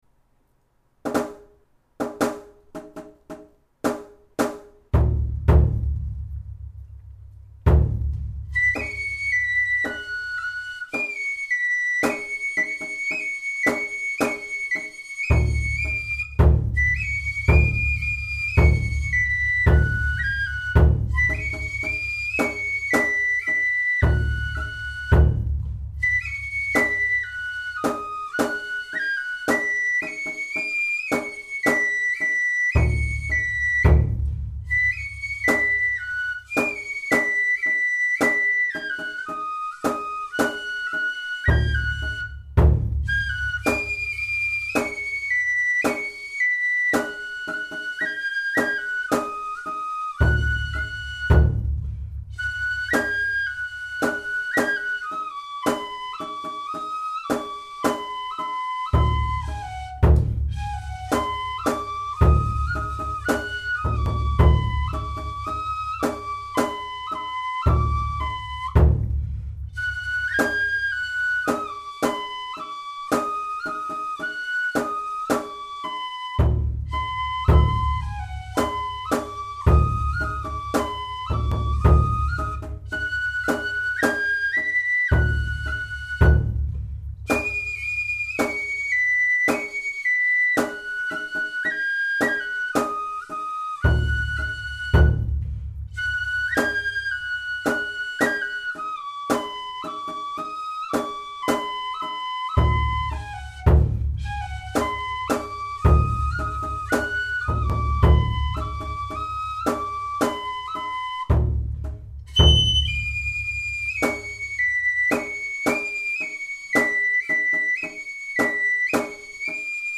このページの音源は、あくまで練習用に録音したもので、装飾音符をすべて省いて、きわめて簡略化された形で吹いています。
太鼓は、コンピューターで作成したもので、リズムは完璧ですが、やや無味乾燥です。
笠寺では、笛が先に始まって後から太鼓が始まる方が一般的ですが、音源に合せて吹きやすいように、太鼓から入るパターンで吹いています。
速度は１１０と、やや遅いテンポで吹いています。また、太鼓の前奏の後、本来、２小節太鼓は休みですが、リズムがとりやすいように、小さな締太鼓を入れてあります。
笛・太鼓